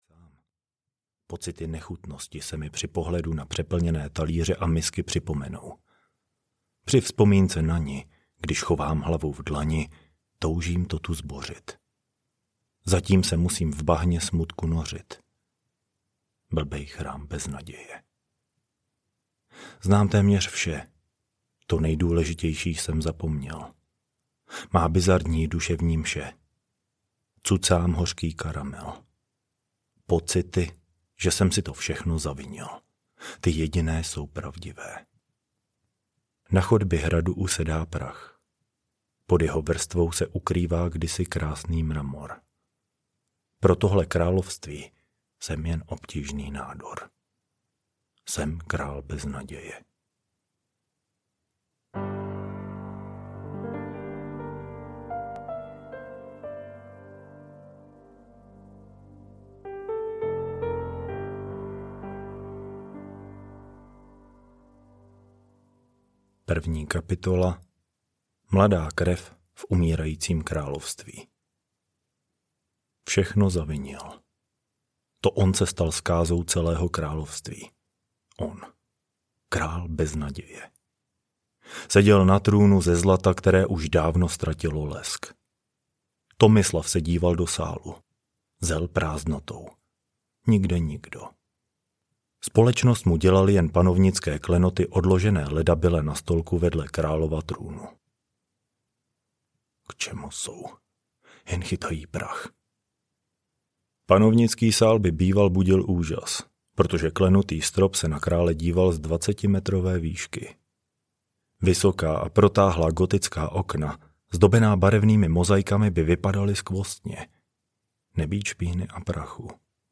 Král Beznaděje audiokniha
Ukázka z knihy